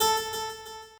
harp5.ogg